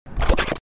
Weird Slide Snatch - Bouton d'effet sonore